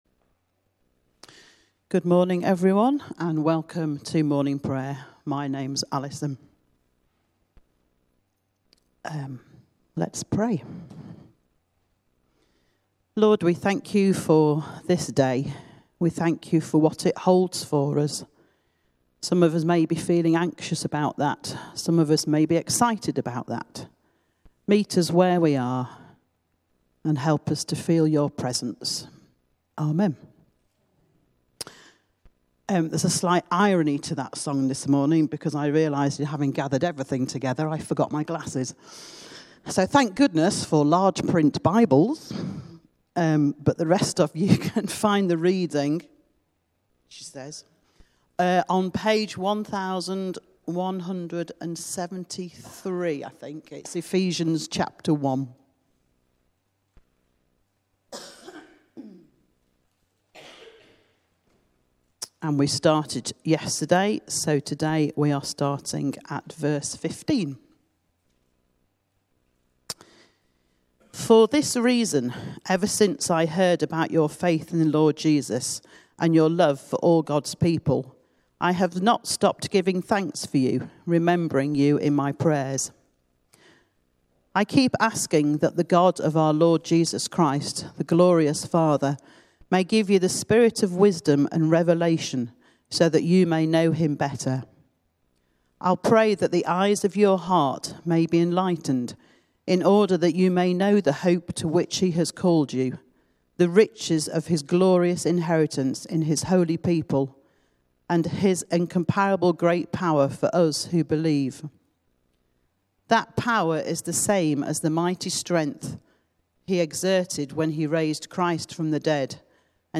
Morning Prayer